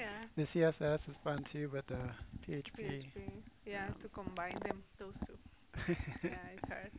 Other Non-Native Audio Examples
4. "php" (empathy): Dimension 6 high